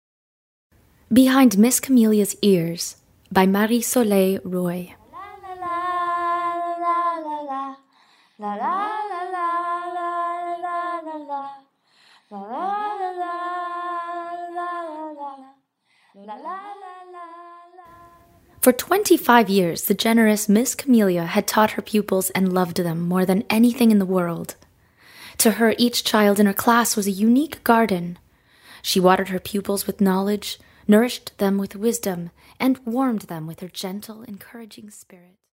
Extrait de l’audio, narration